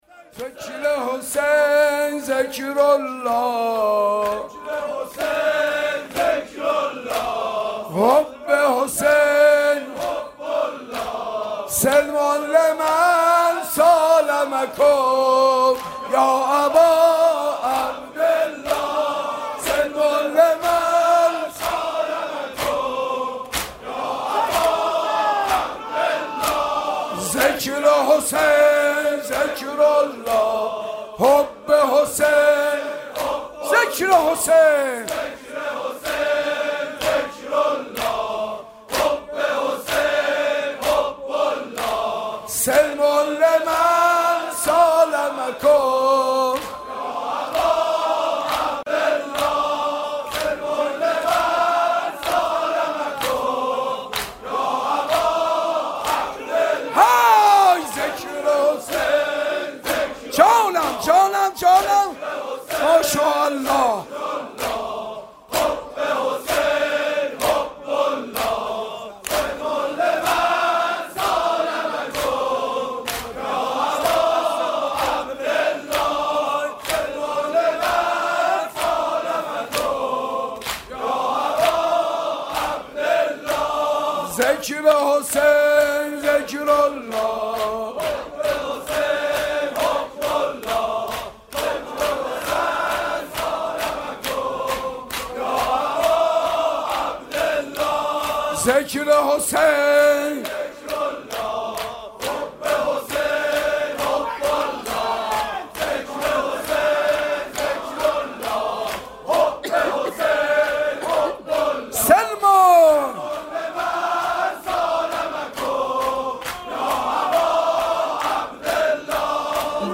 دانلود جدیدترین و گلچین بهترین مداحی های محرم